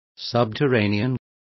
Complete with pronunciation of the translation of subterranean.